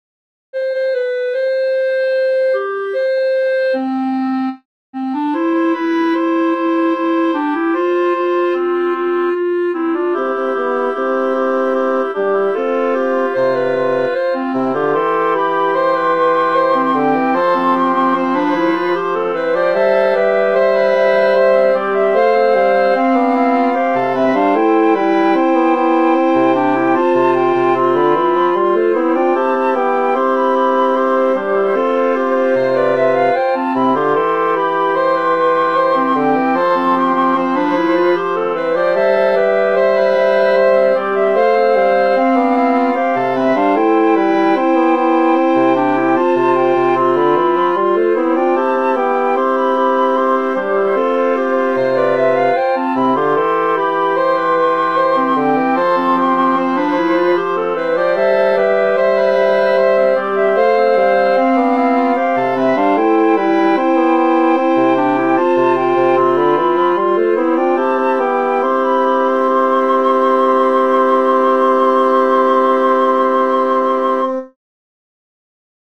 a Children's Song arranged for Woodwind Quartet